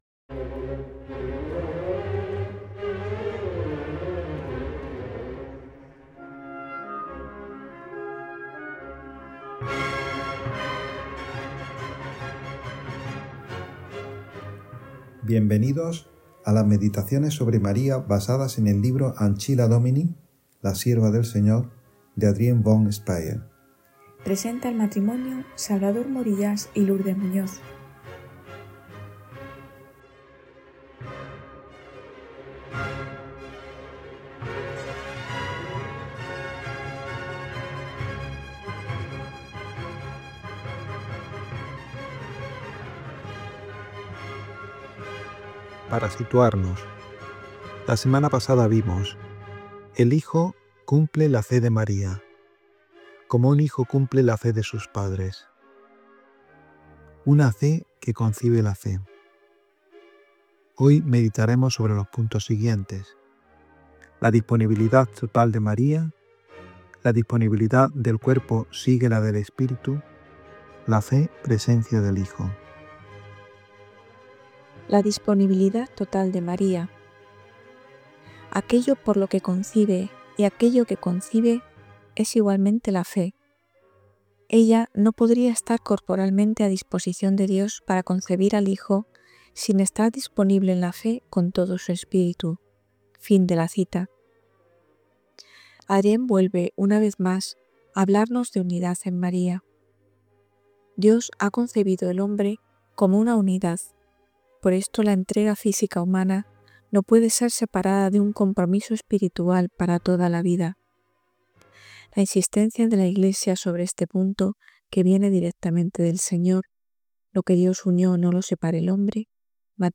El sí de la Virgen María y su entrega sin límites nos presenta el modelo de la actitud cristiana. Este podcast profundiza en esta realidad a través de la lectura y la meditación del libro Ancilla Domini, de la mística suiza Adrienne von Speyr.
Las Contemplaciones marianas de Adrienne von Speyr han sido transmitidas semanalmente en el marco del programa «Vida consagrada» de Radio María España entre noviembre 2022 y octubre 2024.